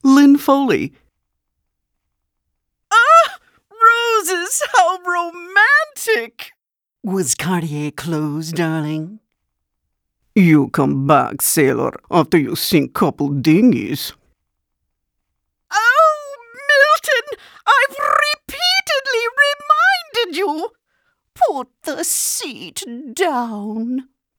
Adult, Mature Adult Has Own Studio